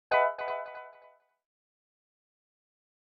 snd_gamover.ogg